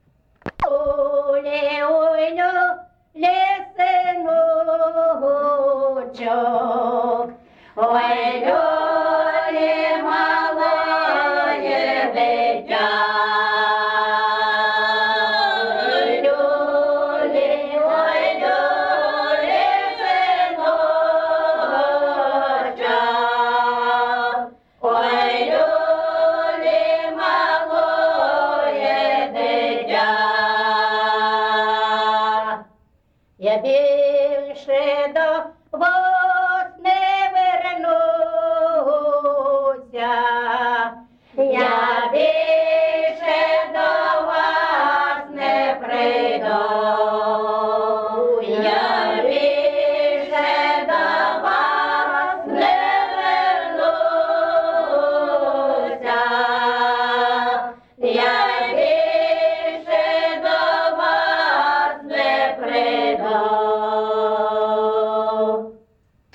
ЖанрРекрутські
Місце записус. Одноробівка, Золочівський район, Харківська обл., Україна, Слобожанщина